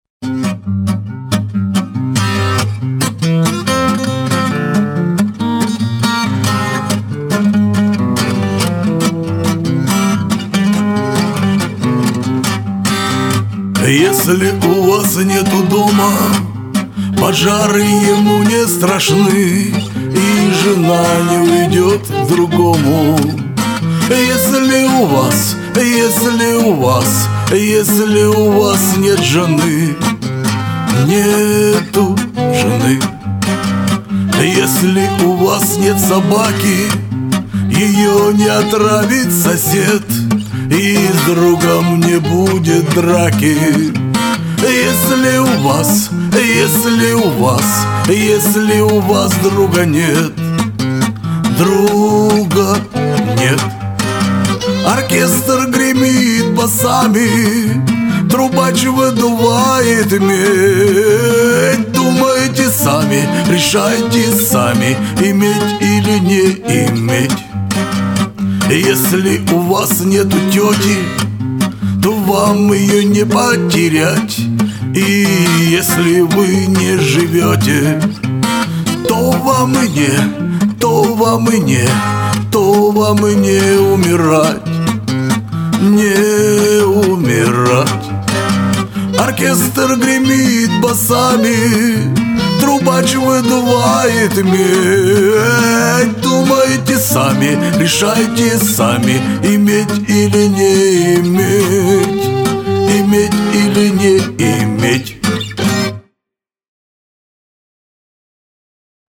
Да и труба с сурдинкой нормально звучит.